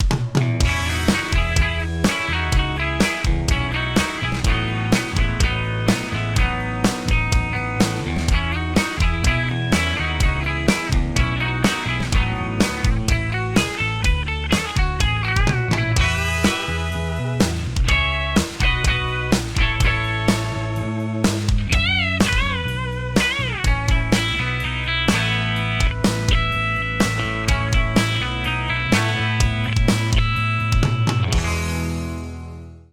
The Pico Platform is extremely transparent with no tonal coloring, making it extremely versatile and easy to work into your existing set up.
Super Squishy Compression
Pico-PlatformCompressor-Limiter-Super-Squishy-Compression.mp3